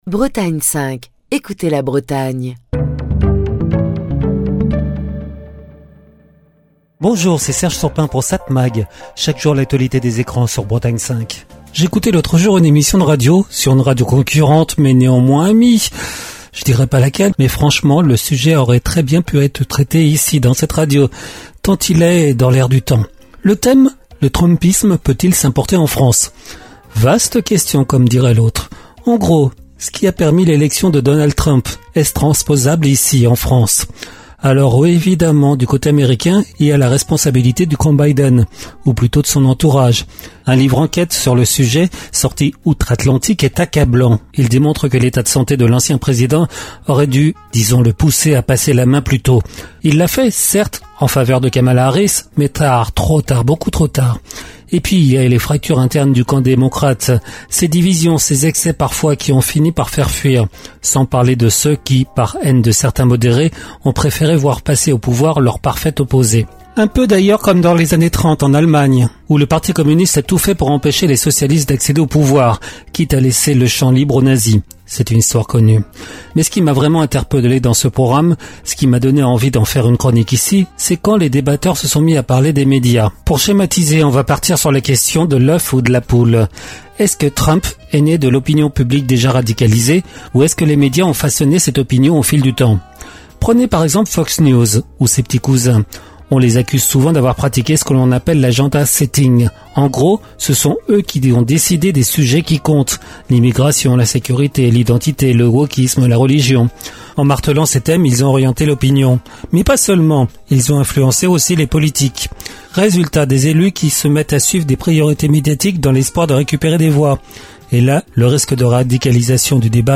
Chronique du 21 mai 2025.